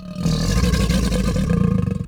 c_croc_bat3.wav